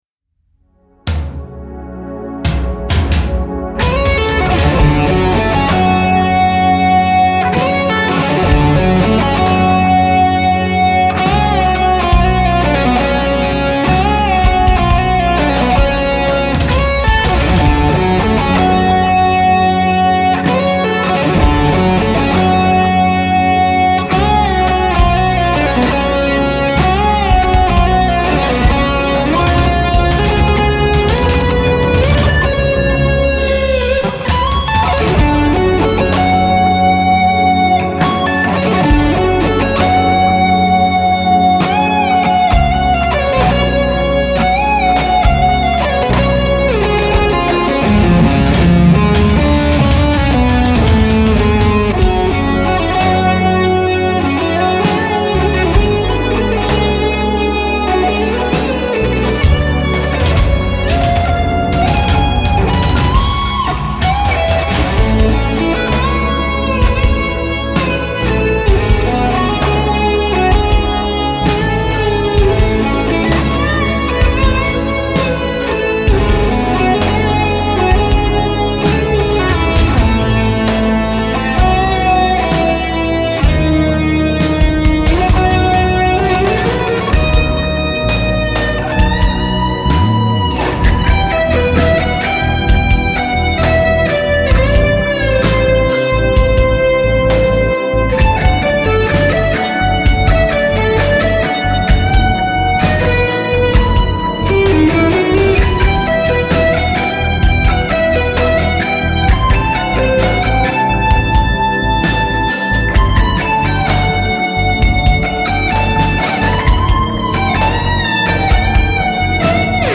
guitar instrumental ballad